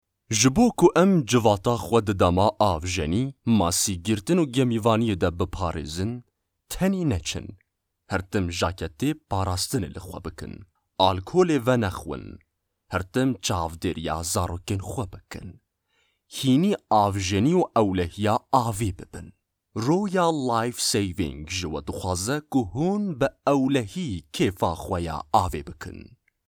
Male
Adult